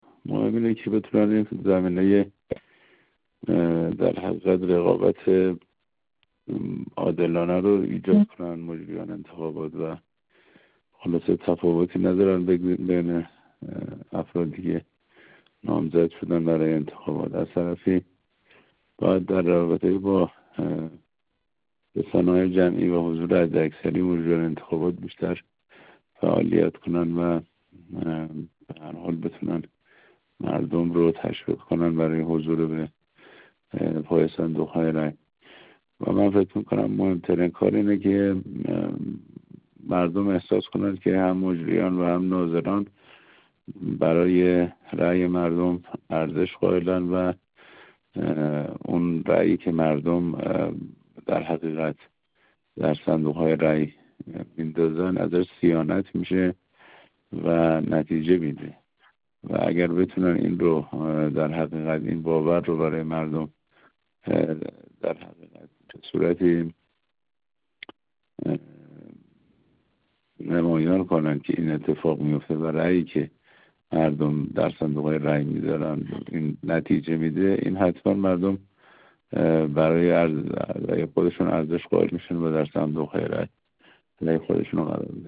حجت‌الاسلام والمسلمین سیدصادق طباطبایی‌نژاد، عضو کمیسیون فرهنگی مجلس شورای اسلامی، در گفت‌وگو با ایکنا درباره نقش مجریان و ناظران انتخابات بر مسئله مشارکت حداکثری و رقابت سیاسی سالم با توجه به تأکیدات مقام معظم رهبری، گفت: مهم این است که مجریان و ناظران انتخابات زمینه رقابت‌ سالم و عادلانه را فراهم کنند.
گفت‌وگو